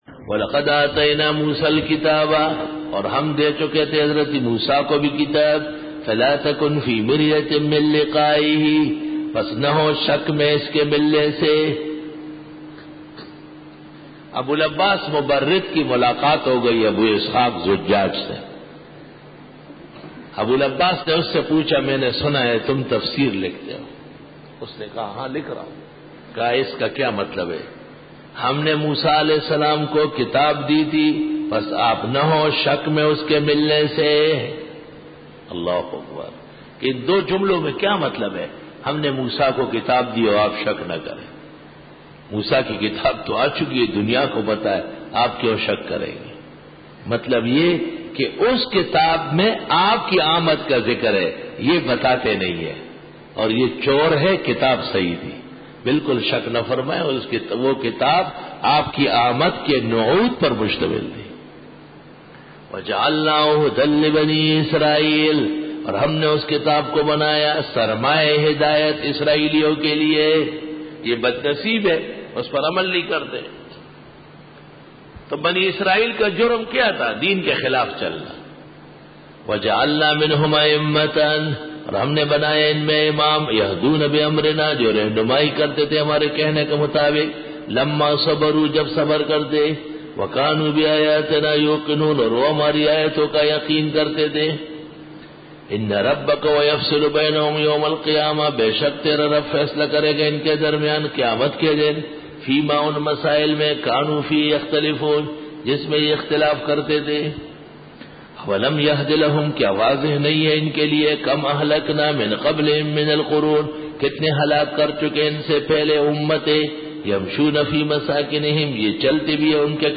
سورۃ آلم السجدہ رکوع-03- Bayan